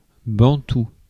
Prononciation
Prononciation France: IPA: [bɑ̃.tu] Le mot recherché trouvé avec ces langues de source: français Les traductions n’ont pas été trouvées pour la langue de destination choisie.